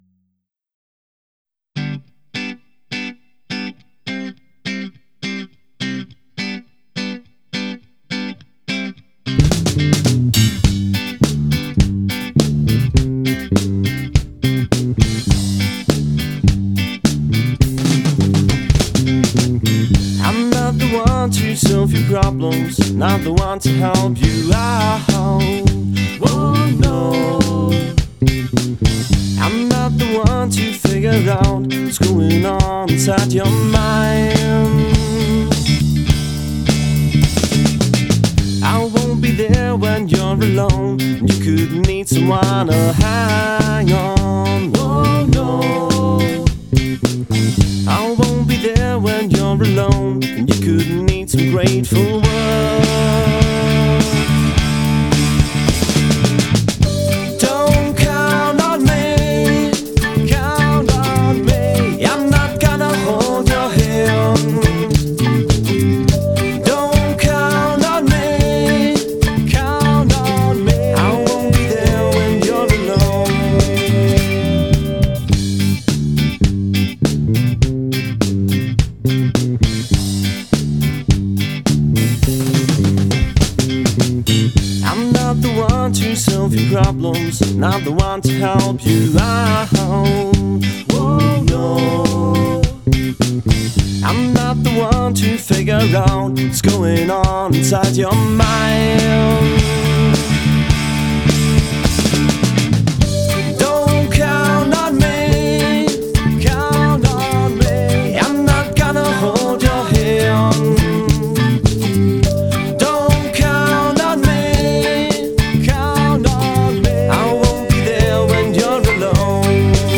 voici la recette proposée par le trio allemand.